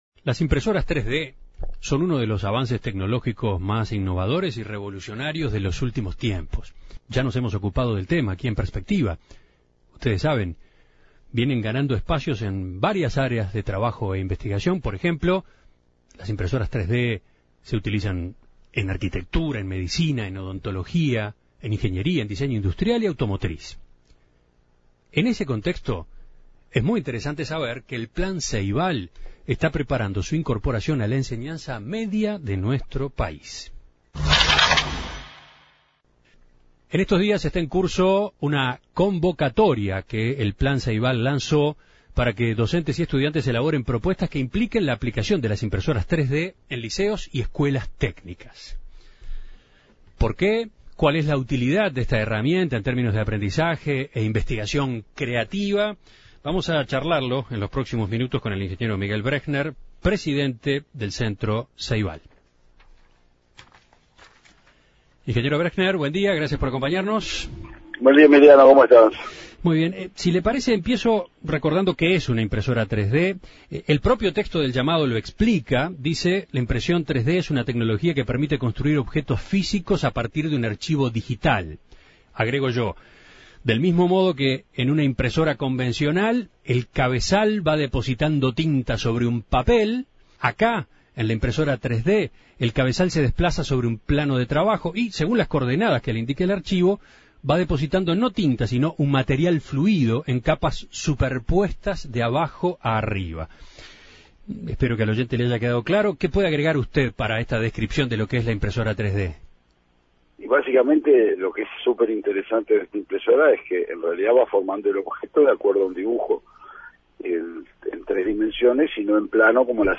En Perspectiva dialogó con el presidente del Centro Ceibal, Miguel Brechner, a propósito de esta nueva iniciativa. El ingeniero dijo que el Plan Ceibal tiene como "mandato" incorporar las nuevas tecnologías en los ámbitos educativos cada vez que surgen.